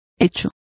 Complete with pronunciation of the translation of deed.